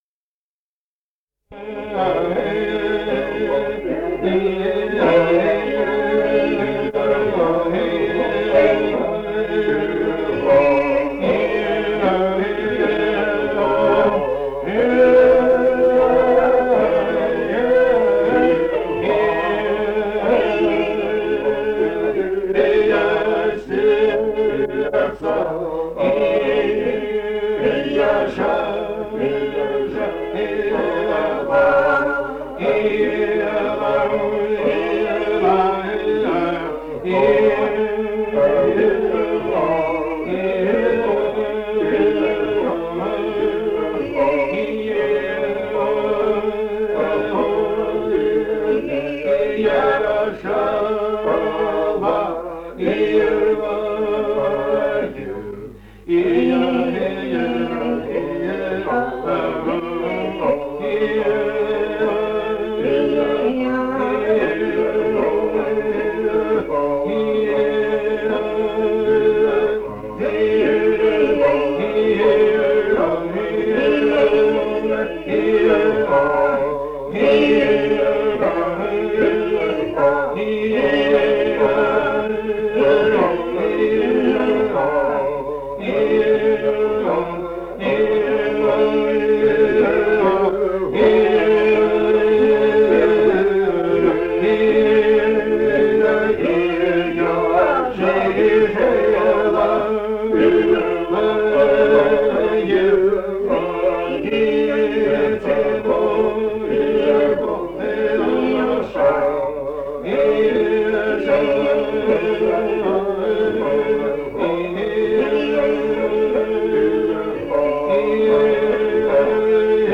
Этномузыкологические исследования и полевые материалы
Псалом 41 (похоронный).
Азербайджан, г. Баку, 1971 г.